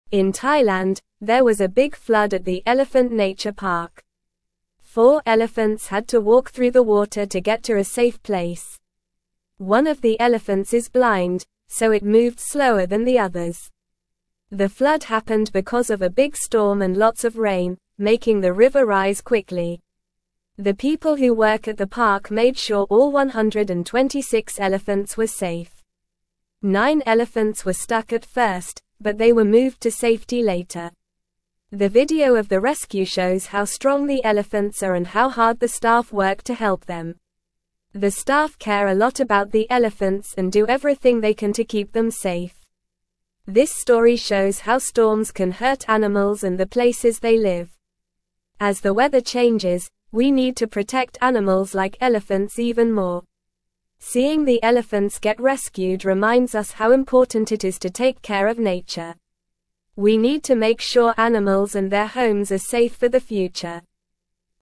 Normal
English-Newsroom-Lower-Intermediate-NORMAL-Reading-Elephants-Brave-Flood-in-Thailand-People-Keep-Safe.mp3